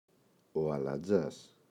αλατζάς, ο [alaꞋndzas]